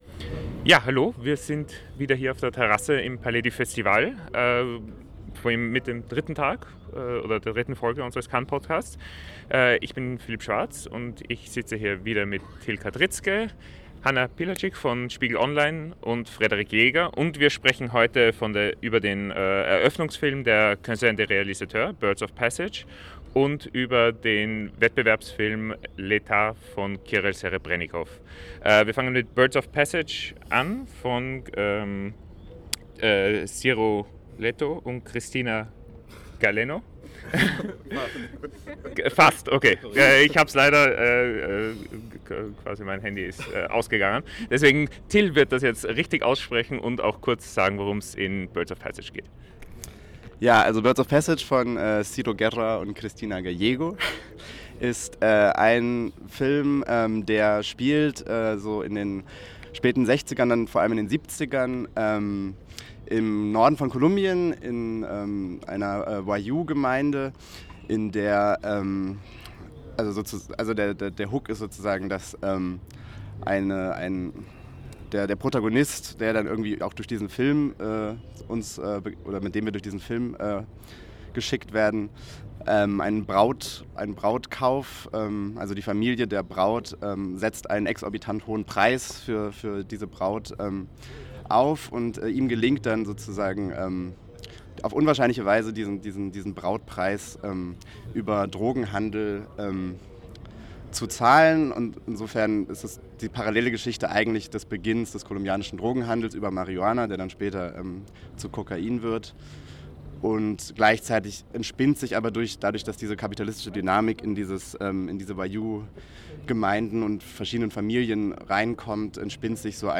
Am dritten Tag beim Festival von Cannes berichten wir vom Eröffnungsfilm der Quinzaine des Réalisateurs, die 2018 ihr 50. Jubiläum feiert und mit dem kolumbianischen Epos Birds of Passage (Pájaros de verano) von Ciro Guerra und Cristina Gallego gestern eröffnet wurde.